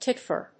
音節tit・fer 発音記号・読み方
/títfɚ(米国英語), títfə(英国英語)/